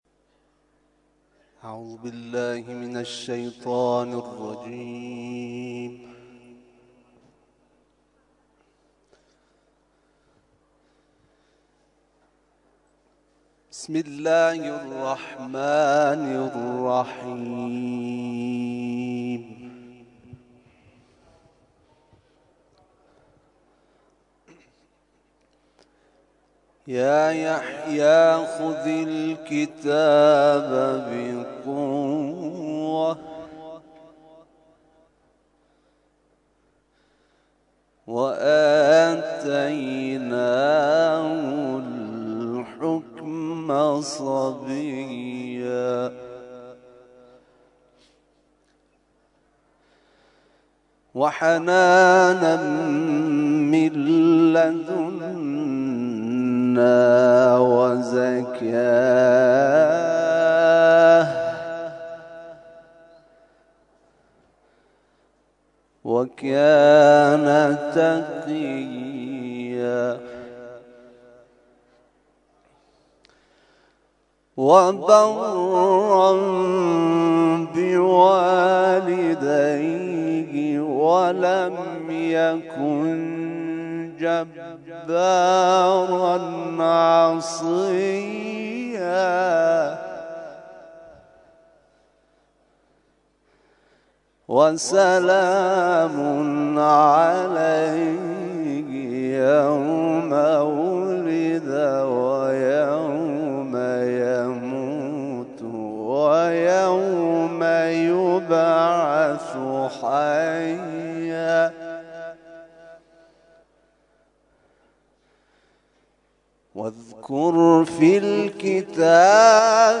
تلاوت ظهر - سوره مریم آیات (۱۲ الی ۲۴) Download